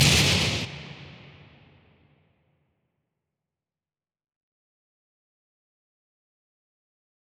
MDMV3 - Hit 12.wav